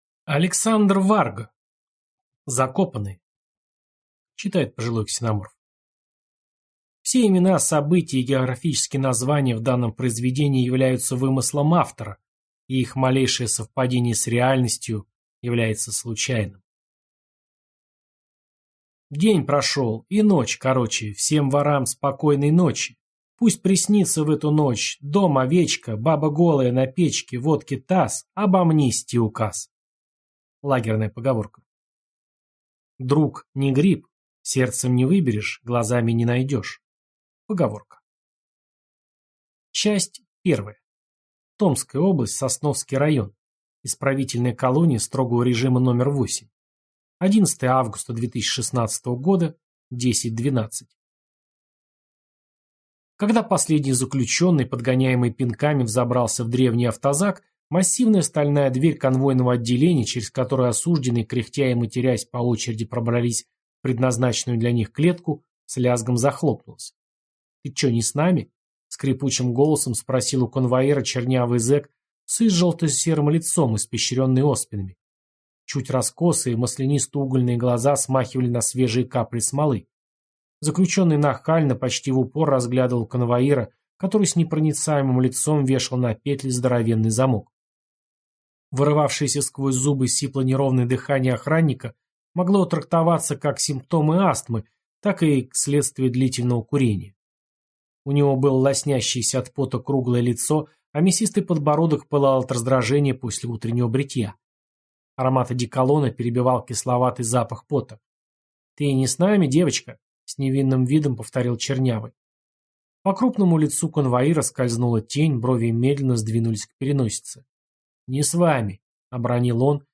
Аудиокнига Закопанные | Библиотека аудиокниг